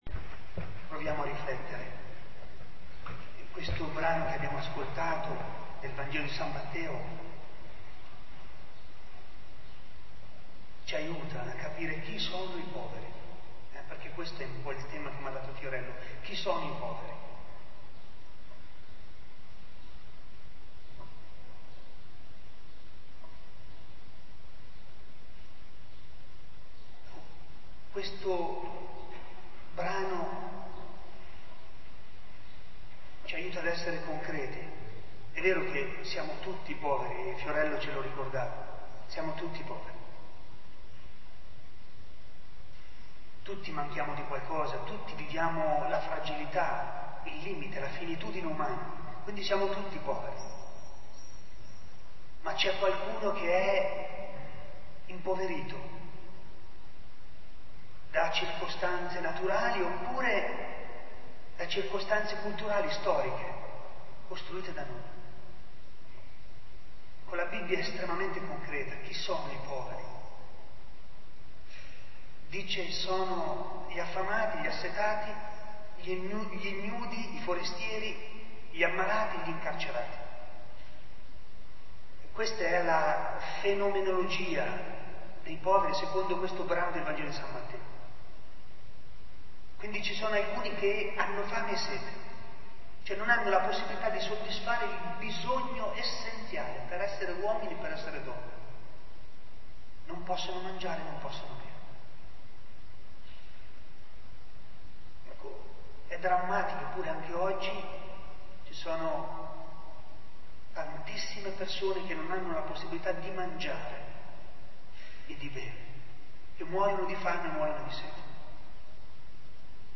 Nella domenica 27 giugno presso la chiesa di S. Pasquale…